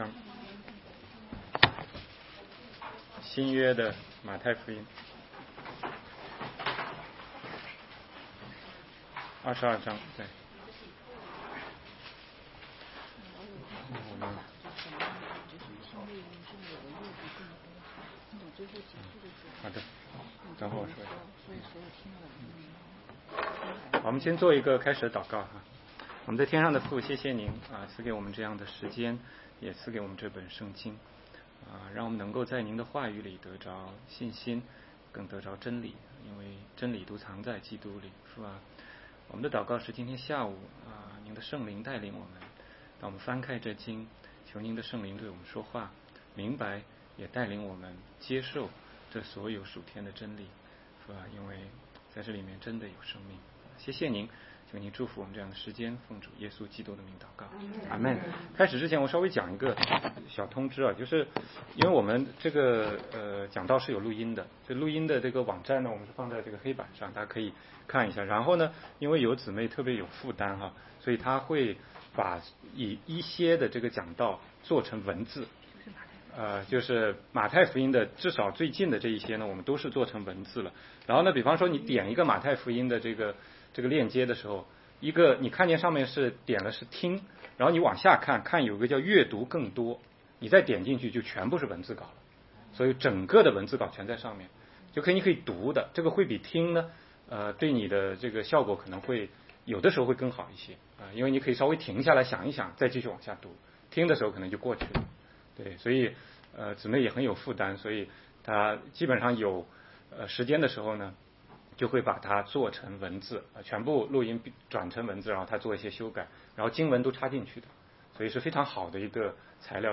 16街讲道录音 - 马太福音22章1-28节：婚宴的比喻和法利赛人的试探